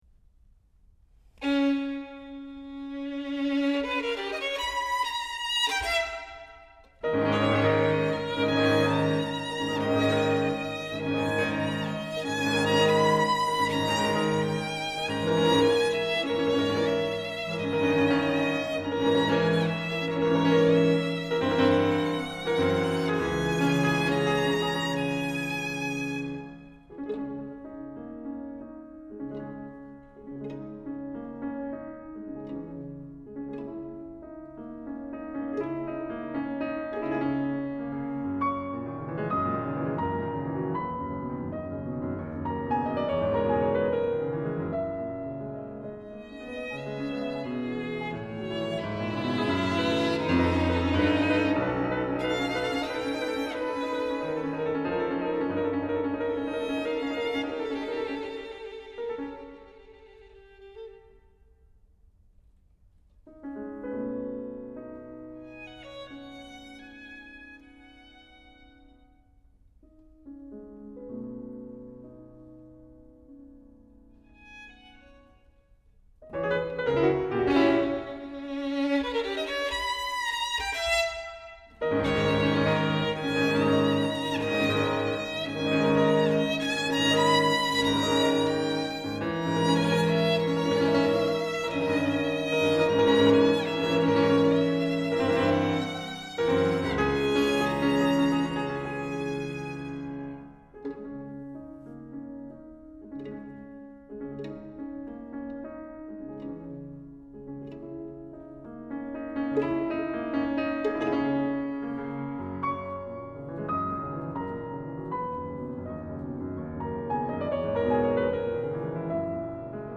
Violine
Klavier